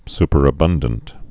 (spər-ə-bŭndənt)